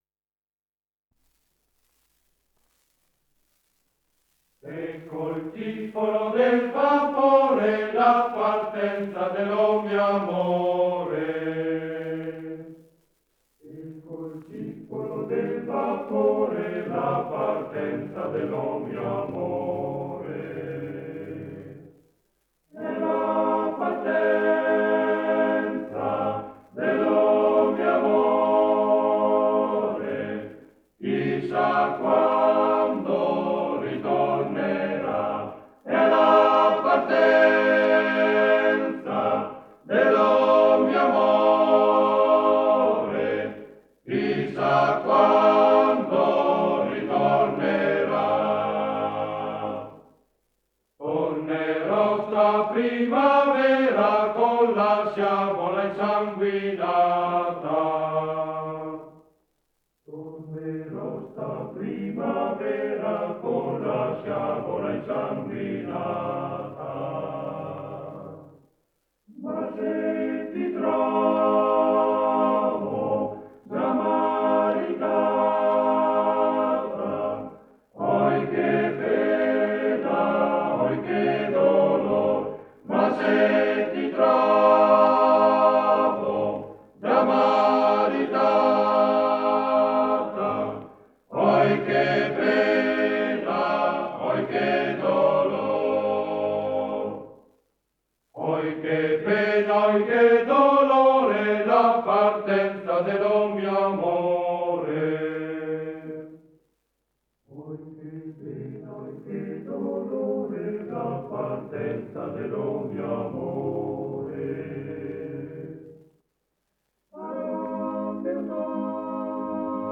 Esecutore: Coro Castel sez. SAT ARCO